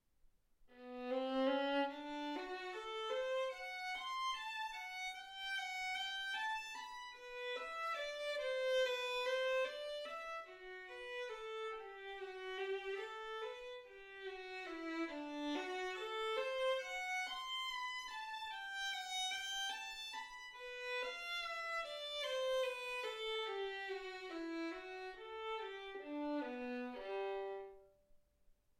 Hegedű etűdök
Classical music